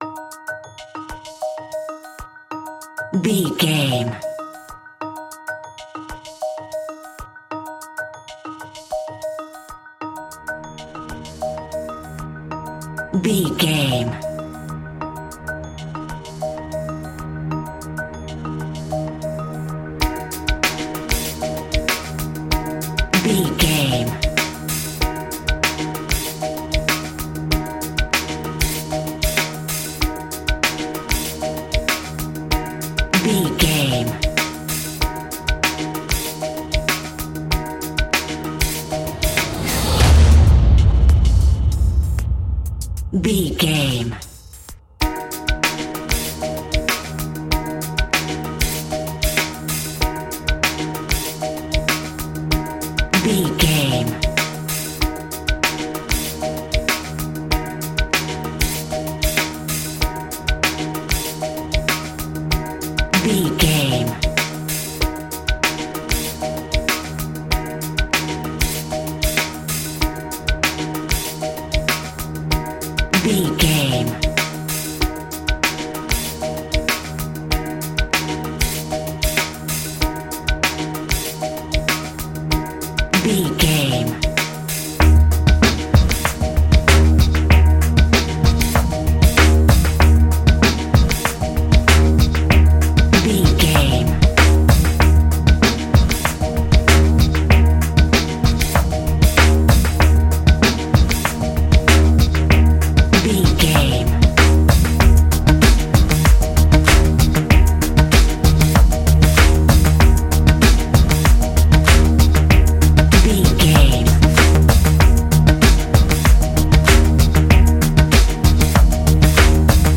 Ionian/Major
D♯
electronic
techno
trance
synths
synthwave